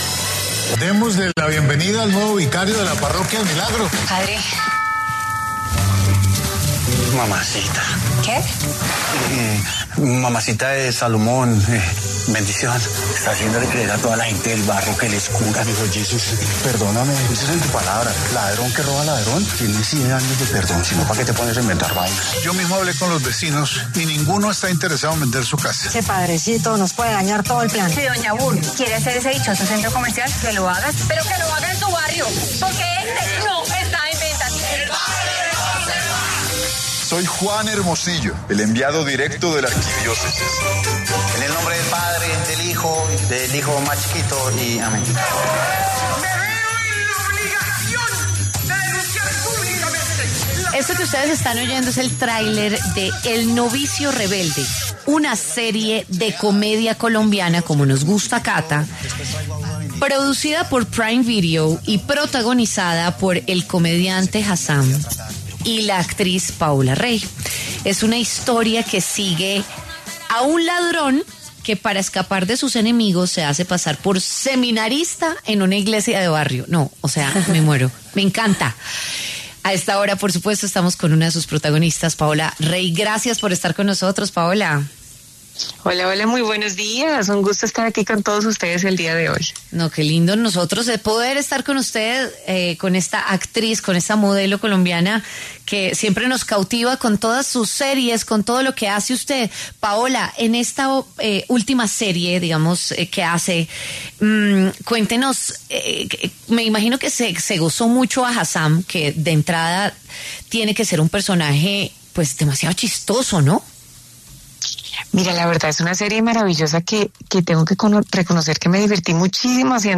En W Fin de Semana, la actriz Paola Rey conversó sobre ‘El Novicio Rebelde’, la serie de Prime Video que protagonizó junto al comediante Hassam.